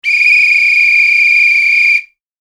笛ピー1.mp3